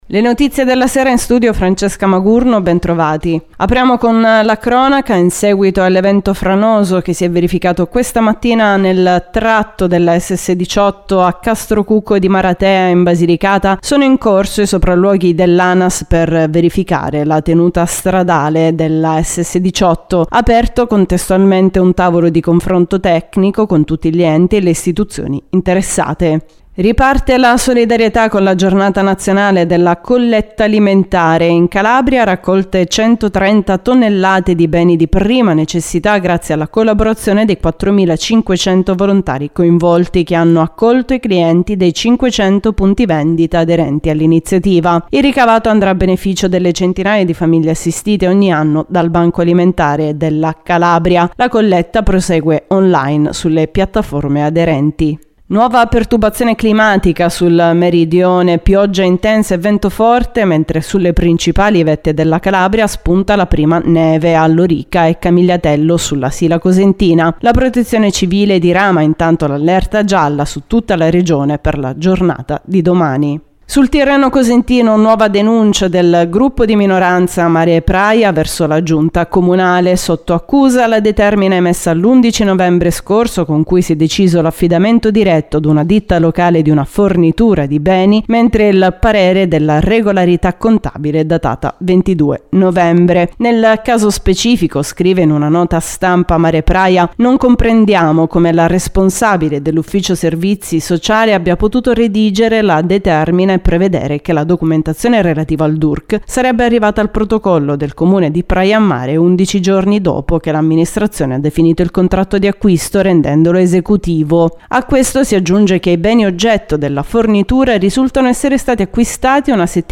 LE NOTIZIE DELLA SERA DI MERCOLEDì 30 NOVEMBRE 2022